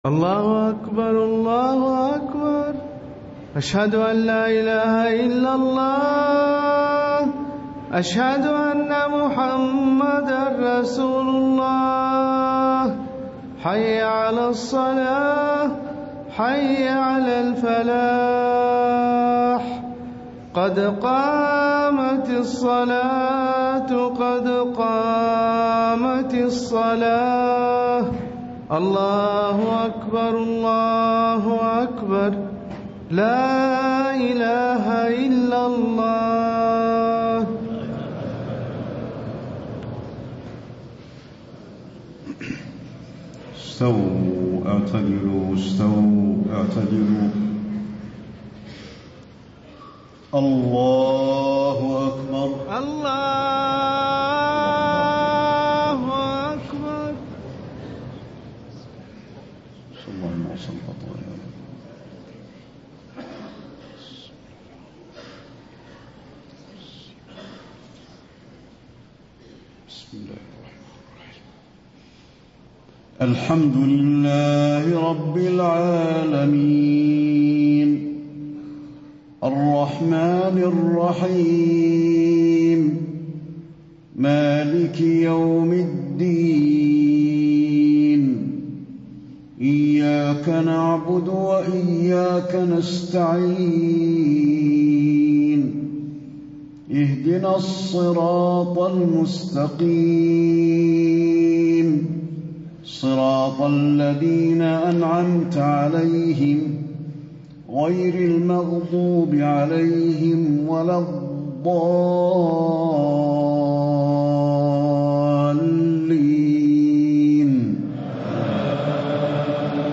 صلاة العشاء 3-2-1435 من سورة فصلت > 1435 🕌 > الفروض - تلاوات الحرمين